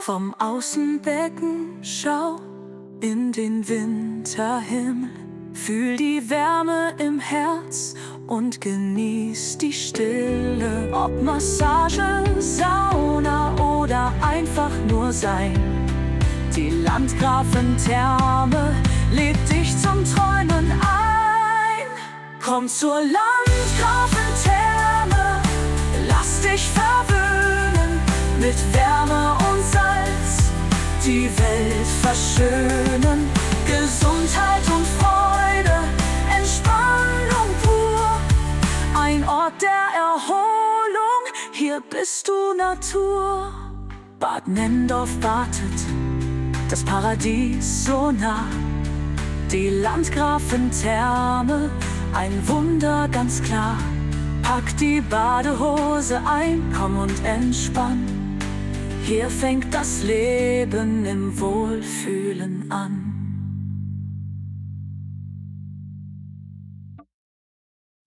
Jingle für Social Media und Imagevideos